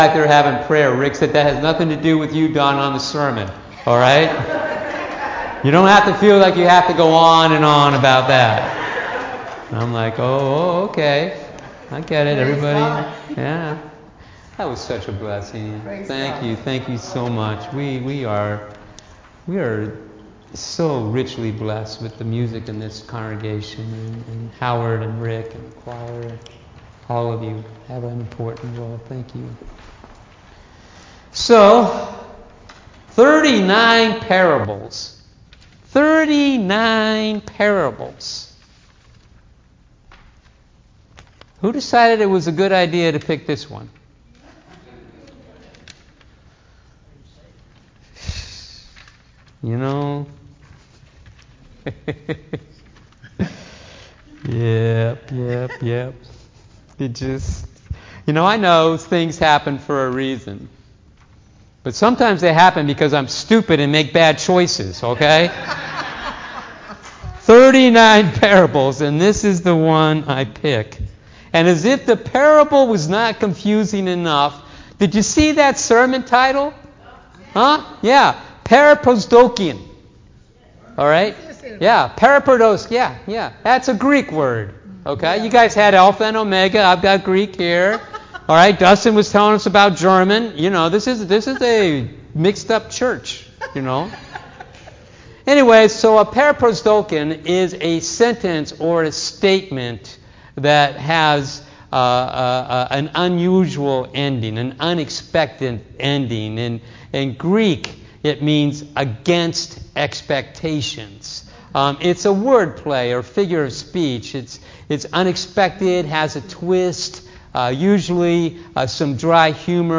Click here to watch this week's full Sunday sermon